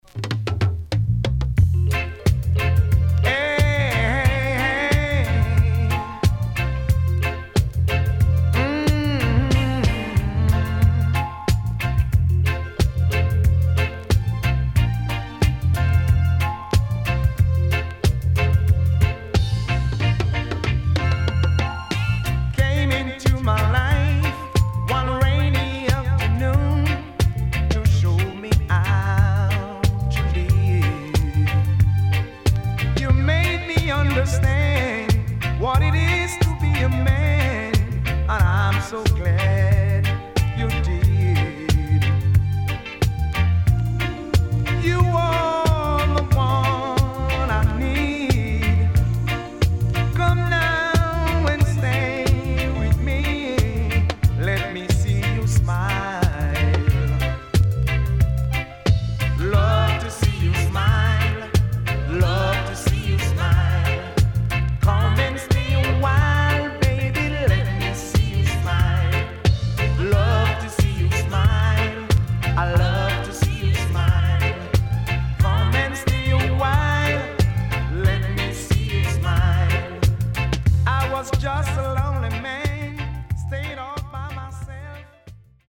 HOME > Back Order [VINTAGE DISCO45]  >  SWEET REGGAE
SIDE A:少しチリノイズ入りますが良好です。